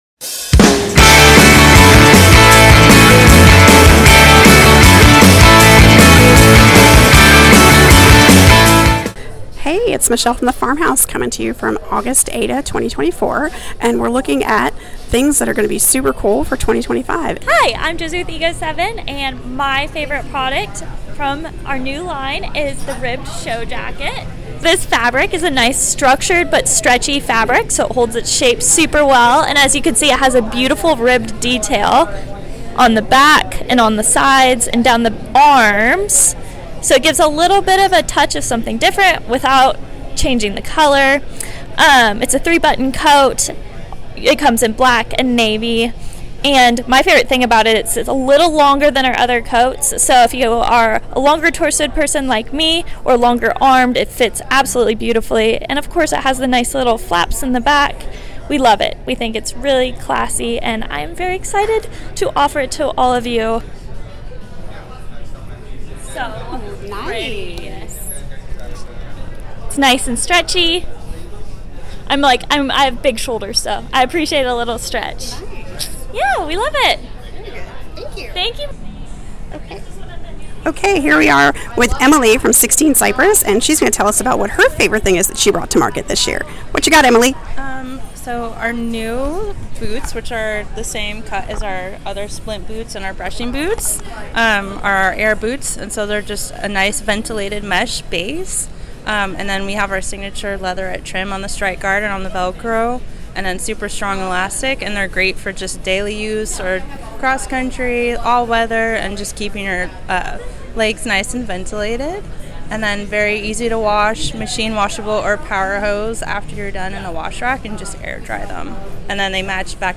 American Equestrian Trade Association's 2024 Dallas show where she asked some of the top equestrian vendors about the most exciting items they're bringing to market next year.